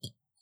BottleFoley4.wav